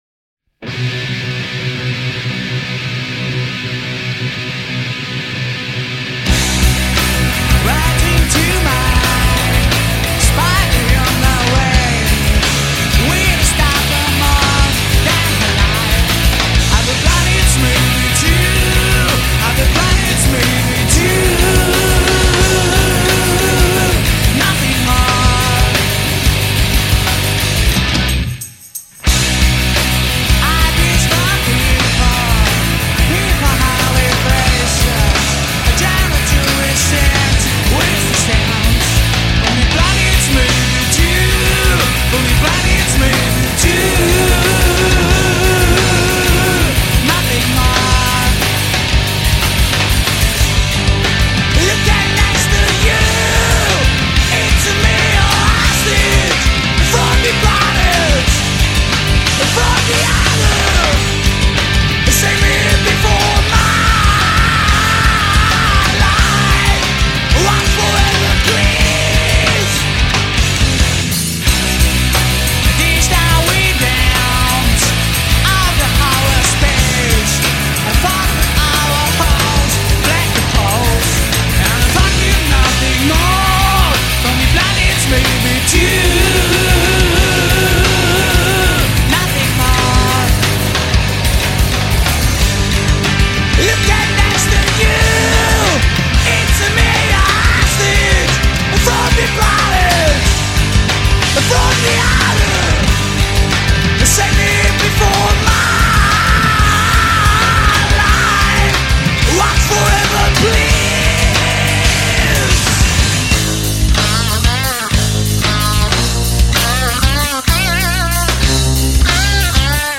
zpěv, kytara
basová kytara
bicí